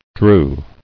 [drew]